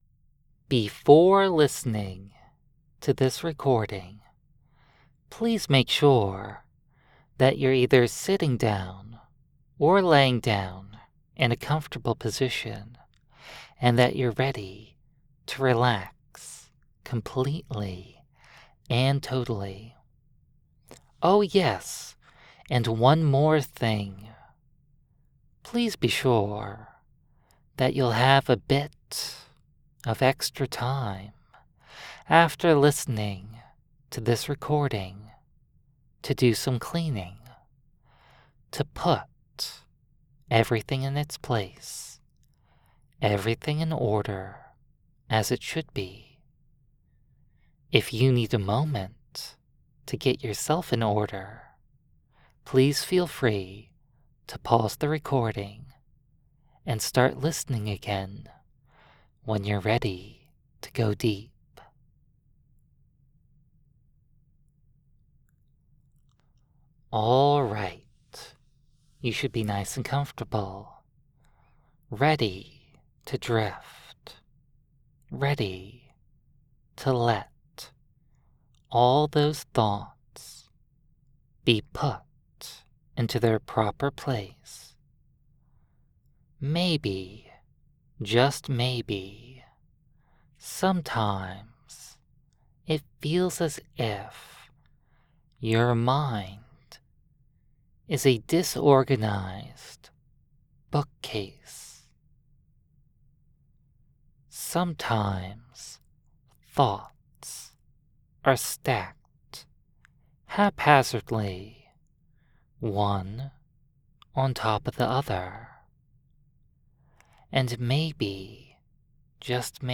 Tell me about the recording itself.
This recording was made live in front of members of my exclusive Discord server.